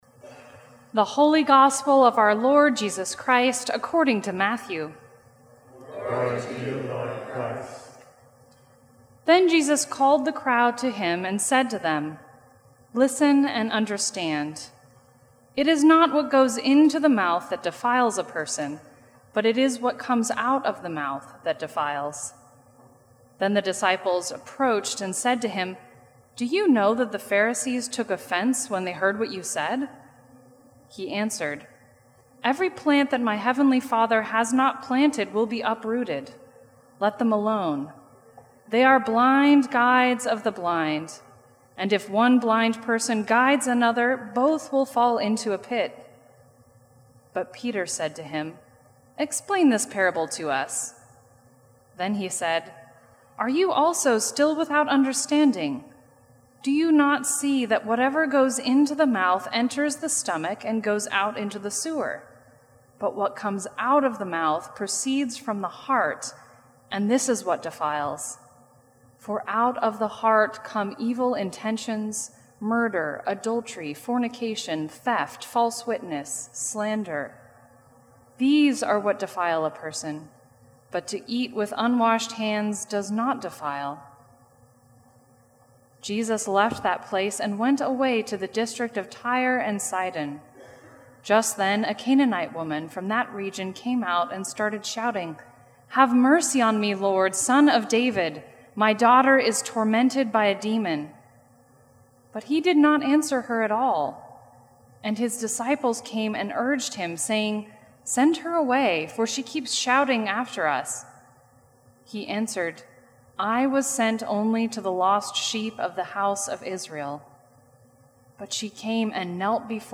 Sermon from the 10 AM service.